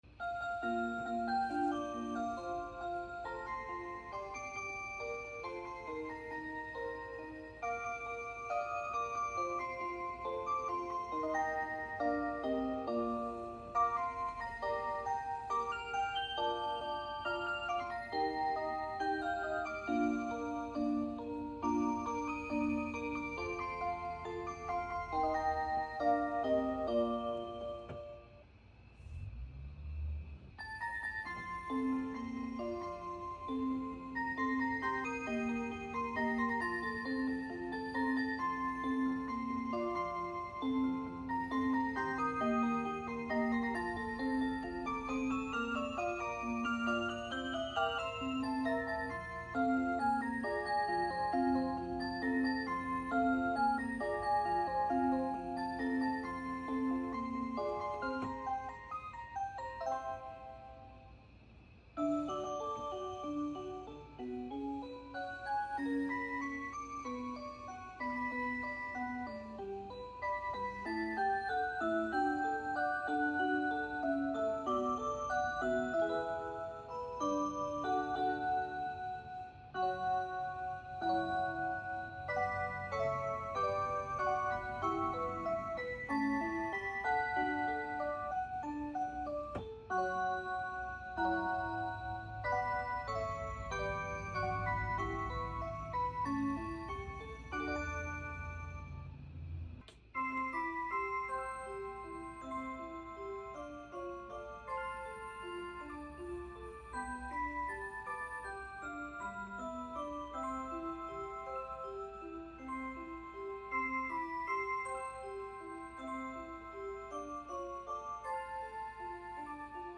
stop scrolling…here’s 30 minutes of different musical clocks for u ☺💞 lots of different sounds and styles hope u enjoy